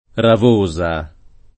[ rav 1S a o rav 1@ a ]